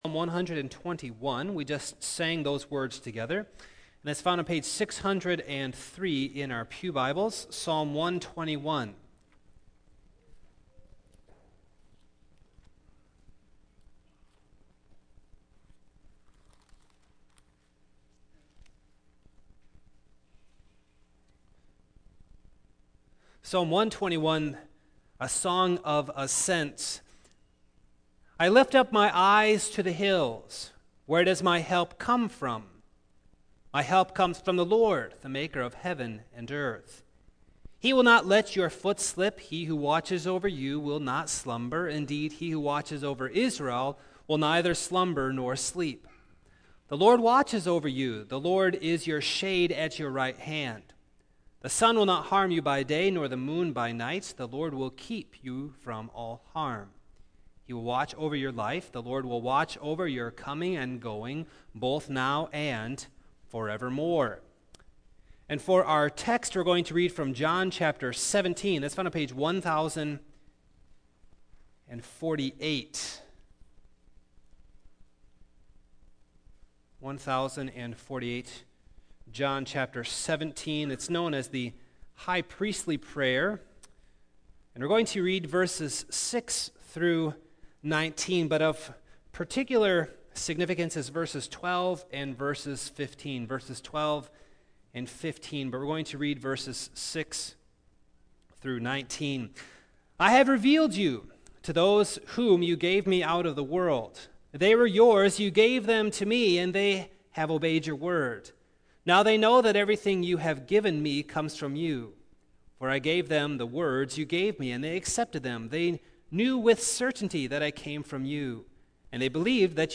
Single Sermons Passage